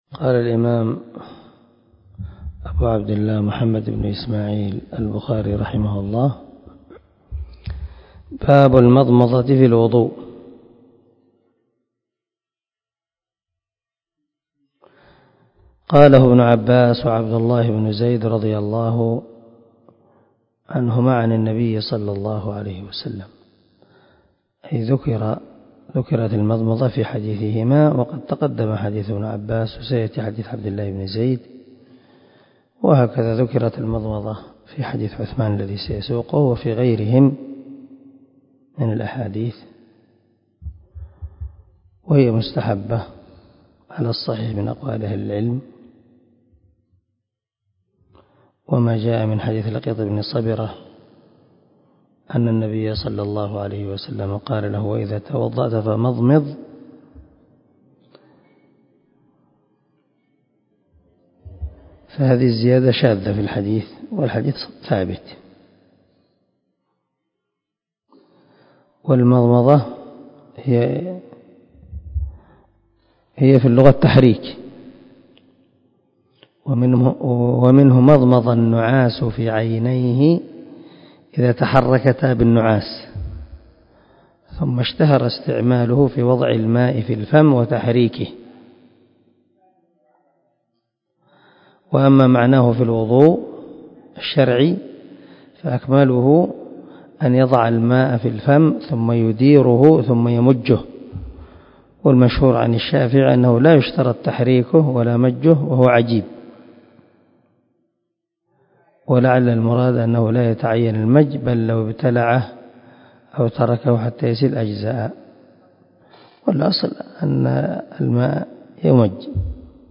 149الدرس 25 من شرح كتاب الوضوء حديث رقم ( 164 ) من صحيح البخاري